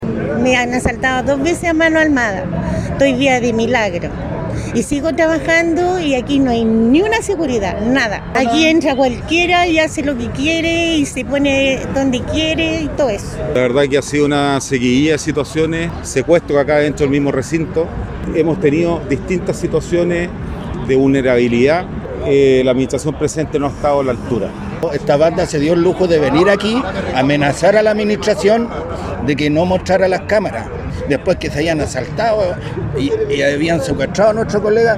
Así lo indicaron los vendedores a los micrófonos de Radio Bío Bío.